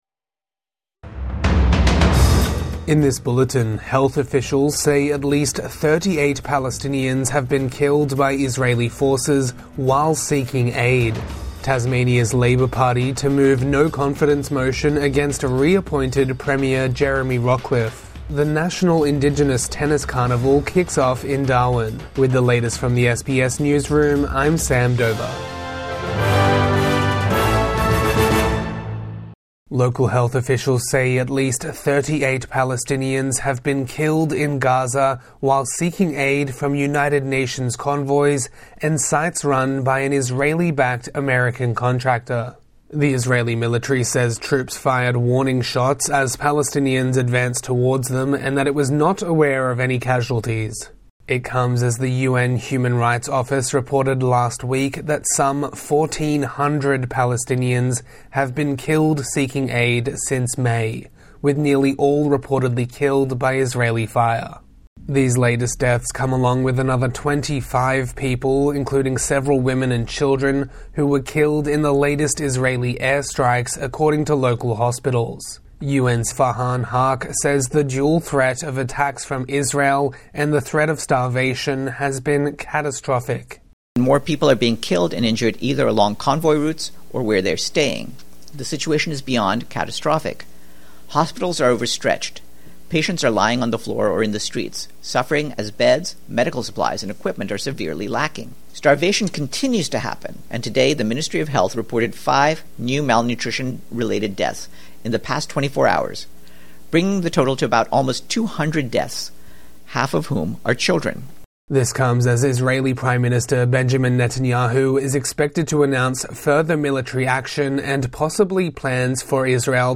Dozens killed in Gaza while seeking aid | Morning News Bulletin 7 August 2025